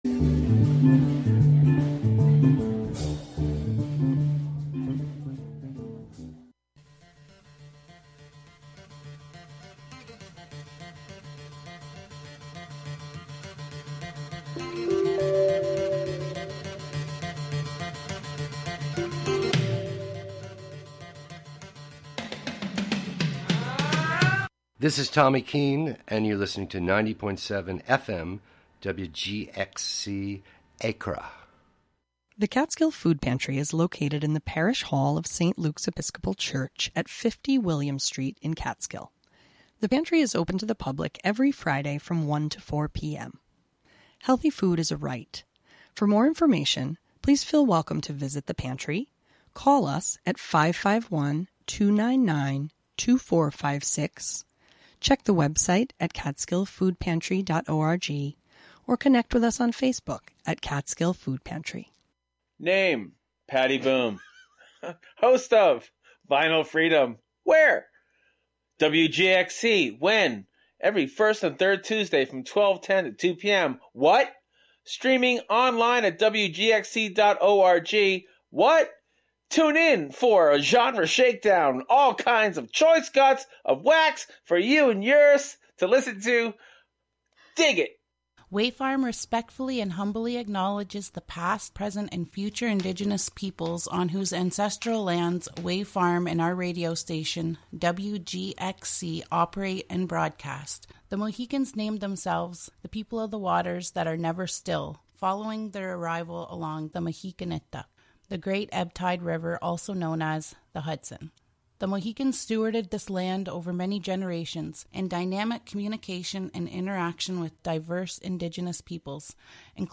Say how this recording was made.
"Geek Speak" entertainment news and reviews, special features, and the "Forgotten Decades" music mix of lesser-played and/or misremembered songs from the '50s through the '90s.